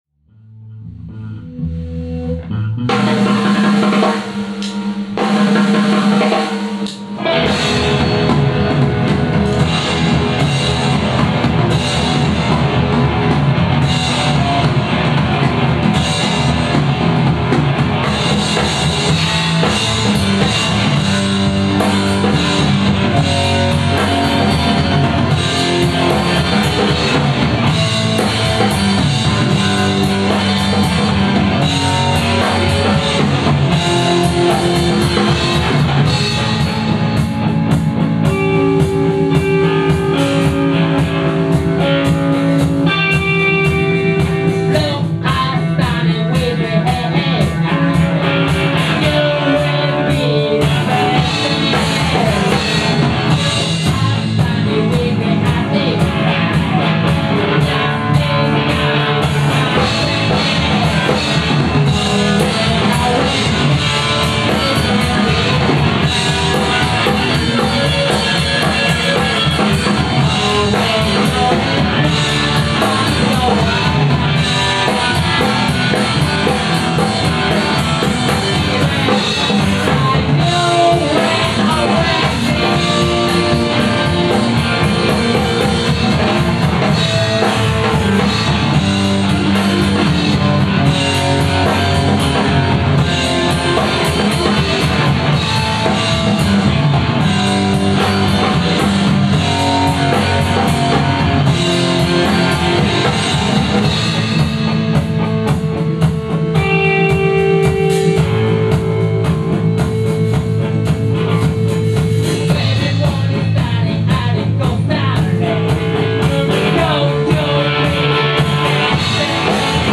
Reflected (Slow) @ Studio Rat, October 16 2010